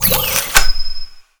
sci-fi_weapon_reload_11.wav